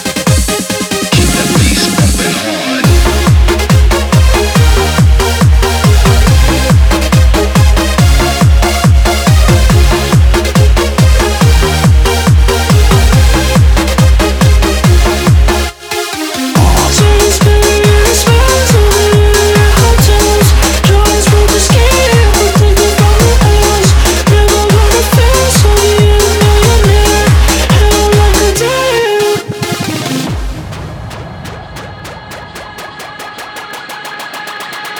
Модульные синтезаторы и глитчи трека
Electronica Electronic
Жанр: Электроника